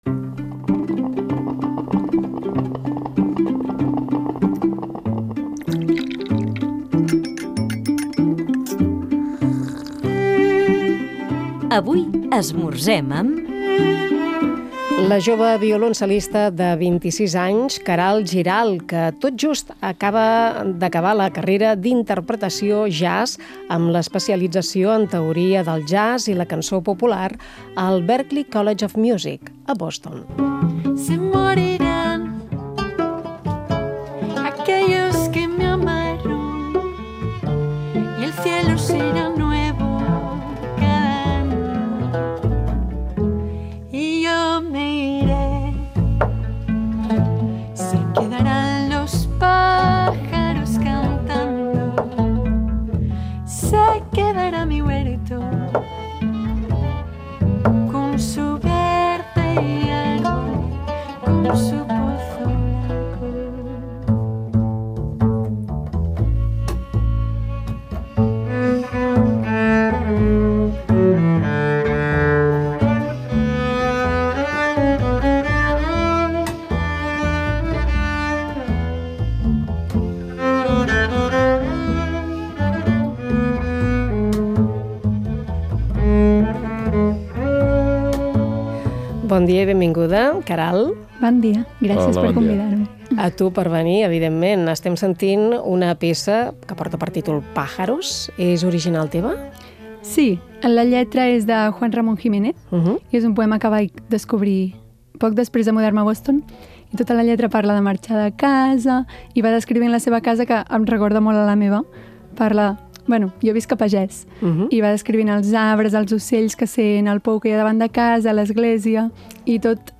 Feature interview on Catalunya Música